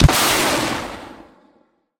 rocket-launcher-3.ogg